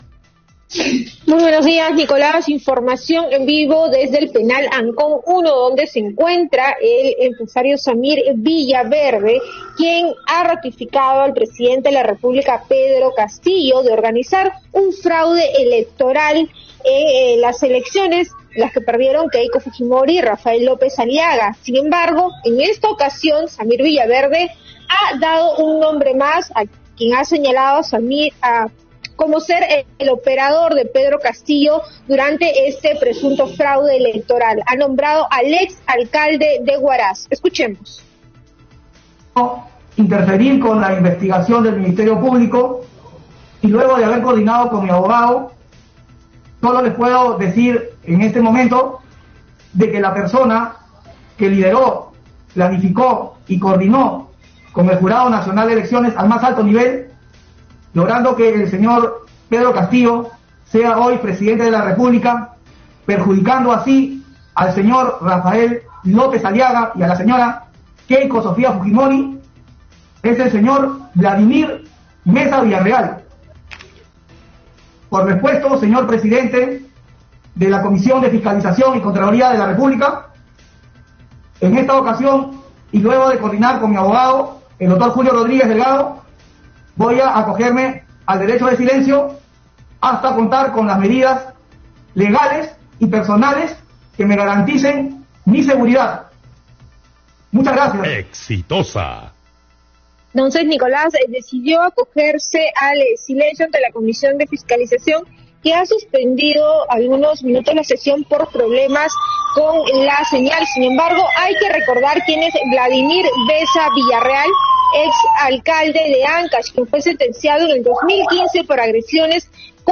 por Radio Exitosa - Lima